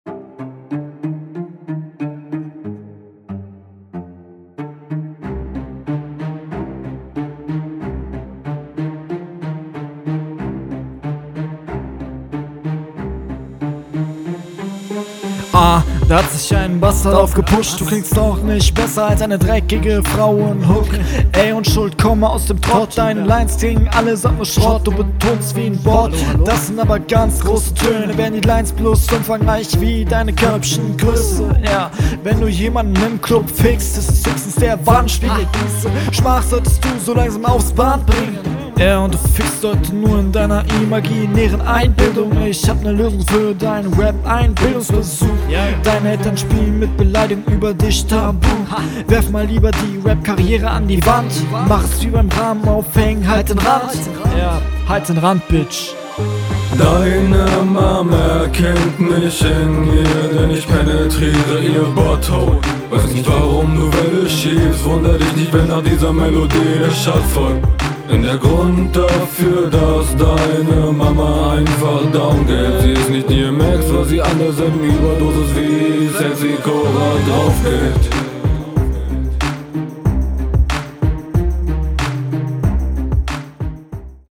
Einstieg kommt etwas unbeholfen. beat ist zu leise. baut in ansätzen schon …